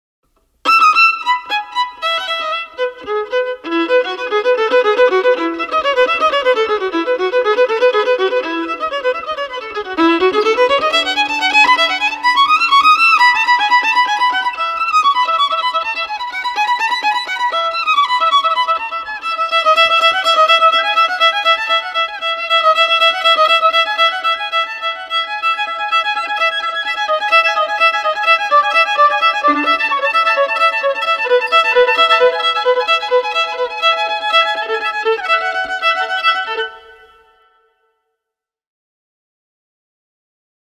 Solo violin tracks recorded at FTM Studio in Denver Colorado
Violin Solo Bach Partita.mp3